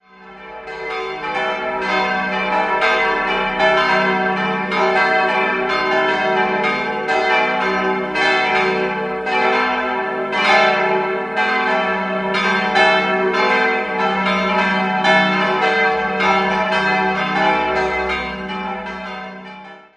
Idealquartett: e'-g'-a'-c'' Die Glocken wurden von Petit&Edelbrock in Gescher gegossen.